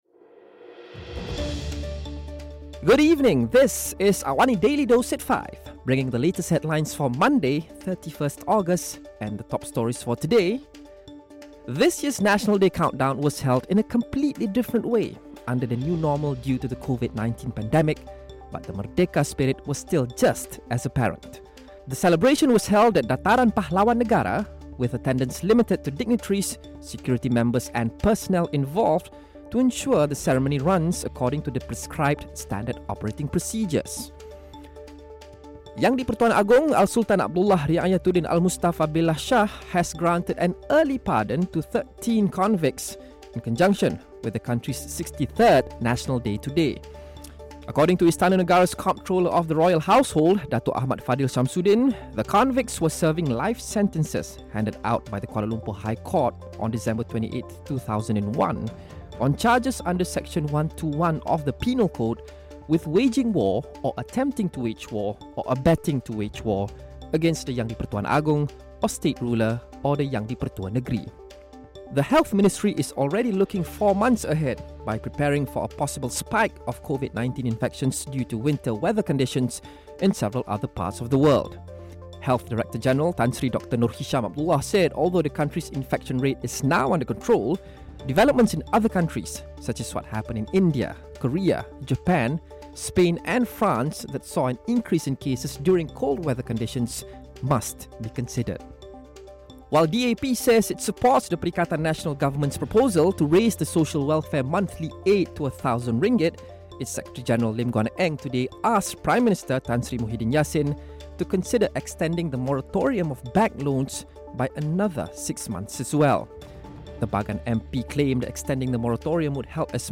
Listen to the top stories of the day, reporting from Astro AWANI newsroom — all in 3 minutes.